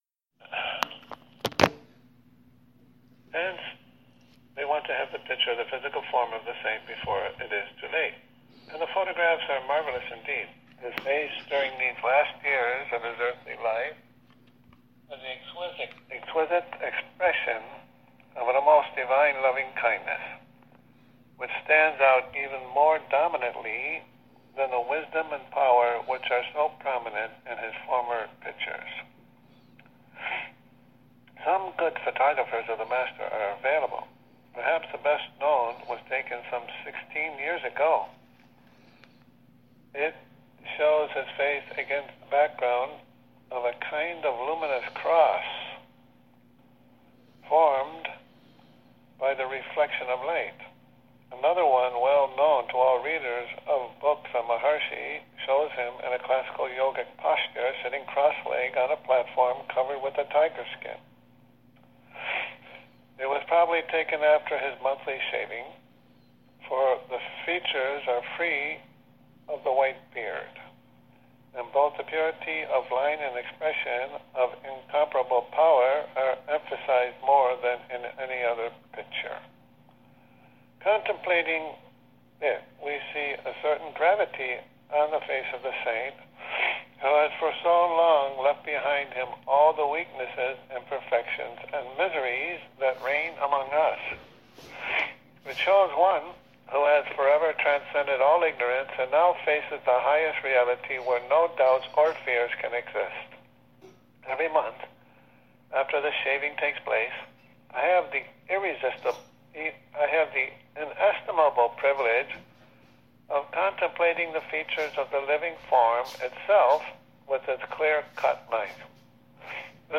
18 Jun 2019 reading in Nova Scotia by phone